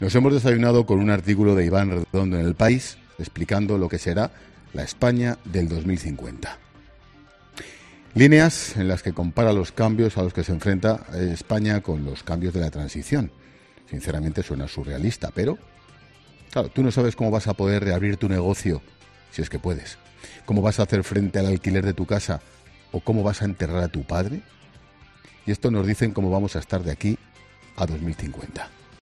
"Tú no sabes si vas a poder reabrir tu negocio, si es que puedes. Cómo vas a hacer frente al alquiler de tu casa o cómo vas a enterrar a tu padre y estos nos dicen cómo vamos a estar aquí al 2050", ha concluido visiblemente molesto Ángel Expósito.